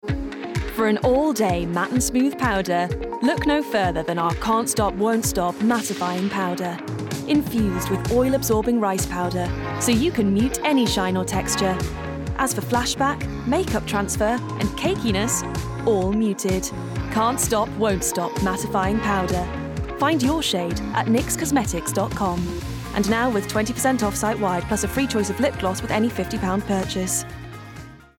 Friendly, Warm, Upbeat
Commerical Clips